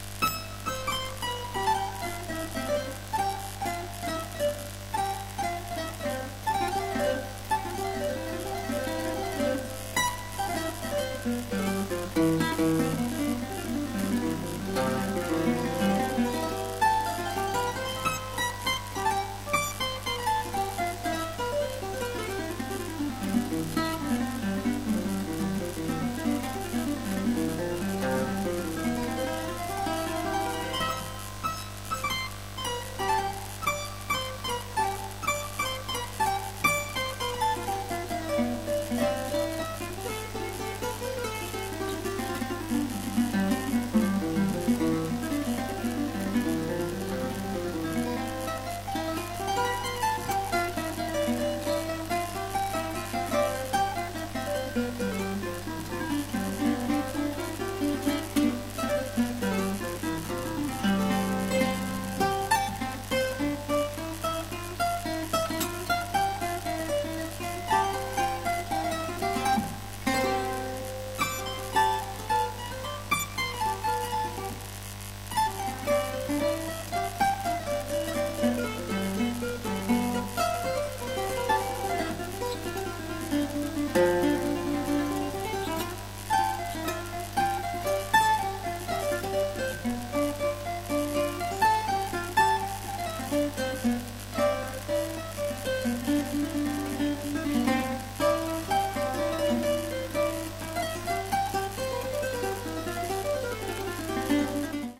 西洋からミャンマーへ沢山の弦楽器が流れてきた1800年代をモチーフにしたミャンマー音楽集！
※レコードの試聴はノイズが入ります。